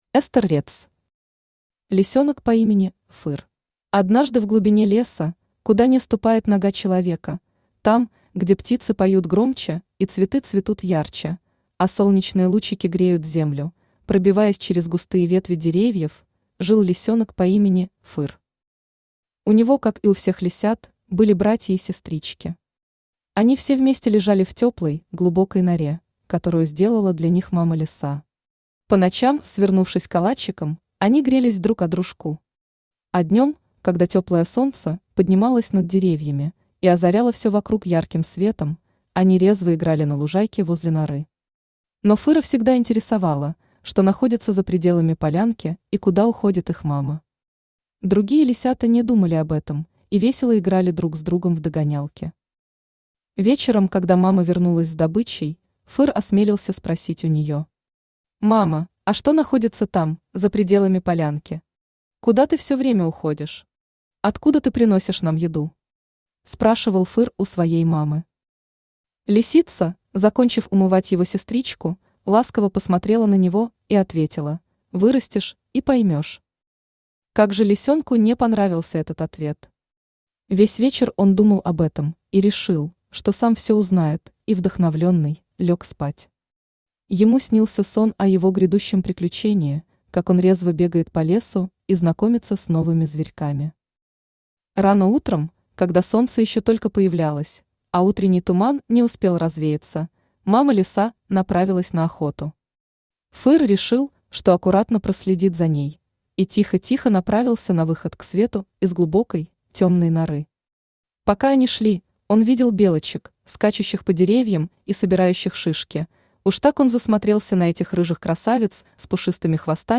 Аудиокнига Лисёнок по имени Фыр | Библиотека аудиокниг